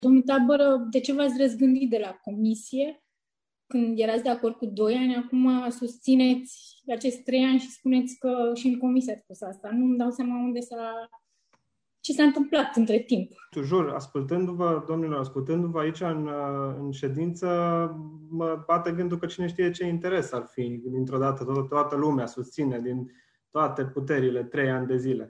Dispute aprinse în Consiliul Local Timișoara pe marginea unui proiect de hotărâre care viza prelungirea contractului de închiriere pentru cafeneaua Simphony, aflată la parterul Operei Naționale din Timișoara.
Consilierii USR-PLUS nu au fost de acord cu prelungirea pe trei ani, insinuând, prin vocea Anei Munteanu și a lui Dan Reșitnec, că în spatele propunerii sunt anumite interese.